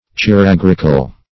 Search Result for " chiragrical" : The Collaborative International Dictionary of English v.0.48: Chiragrical \Chi*rag"ric*al\, a. Having the gout in the hand, or subject to that disease.
chiragrical.mp3